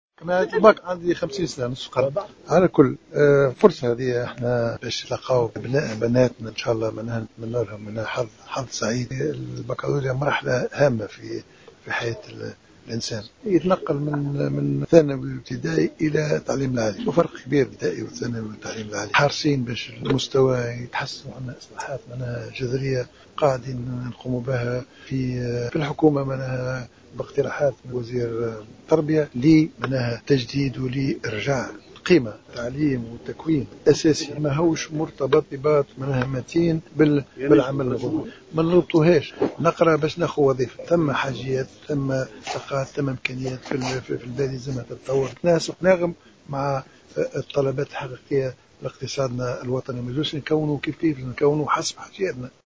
أدى رئيس الحكومة، الحبيب الصيد اليوم زيارة إلى معهد خير الدين باشا من ولاية أريانة، وذلك لمواكبة امتحانات البكالوريا في دورتها الرئيسية.